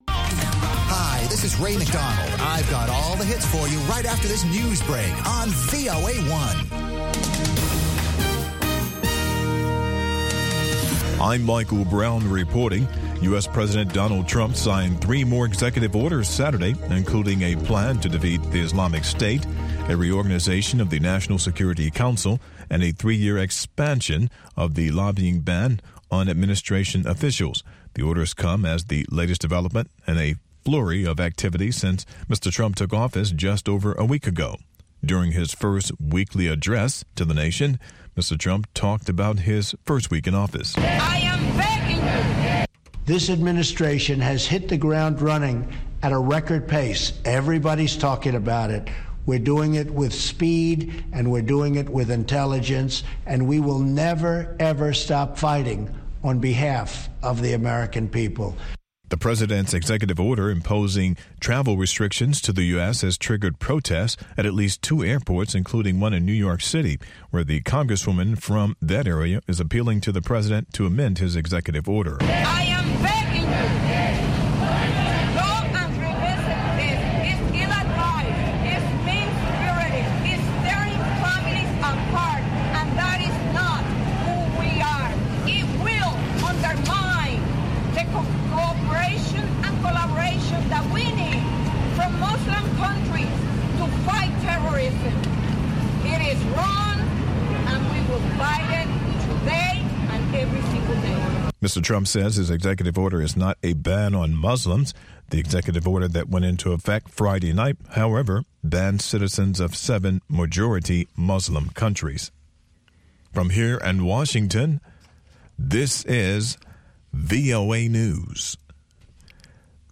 اخبار